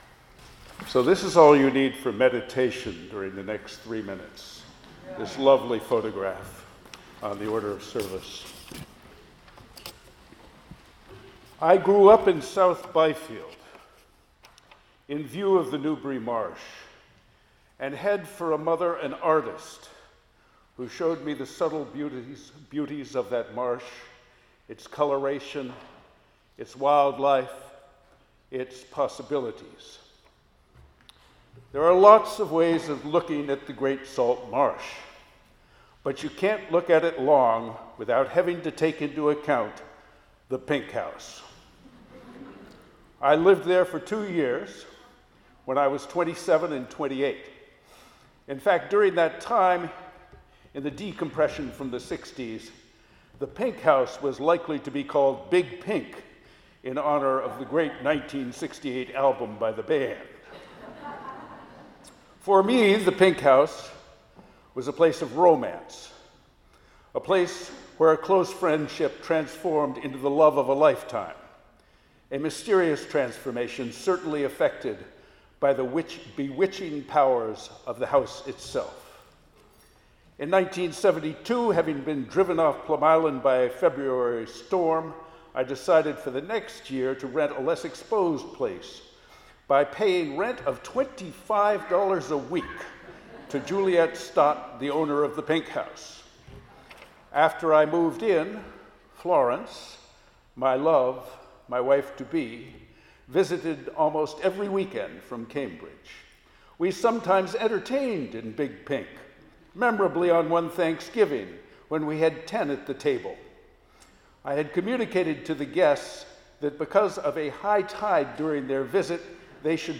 Listen to the Reading & Sermon